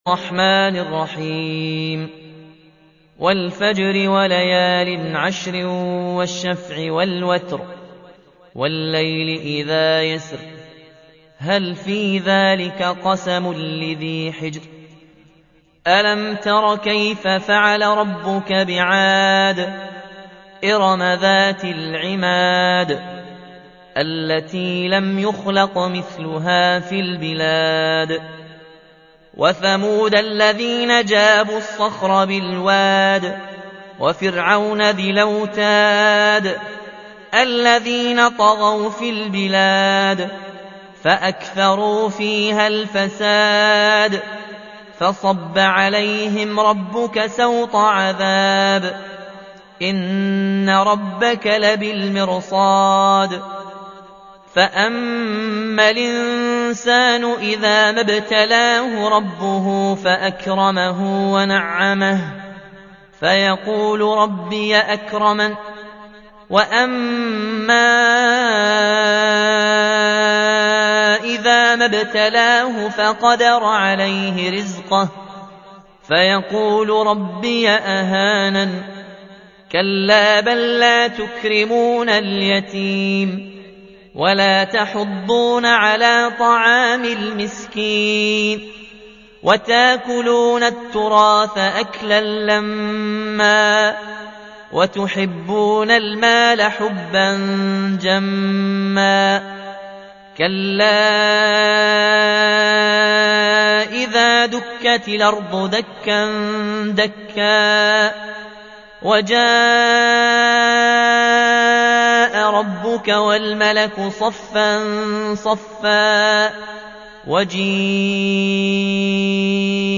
تحميل : 89. سورة الفجر / القارئ ياسين الجزائري / القرآن الكريم / موقع يا حسين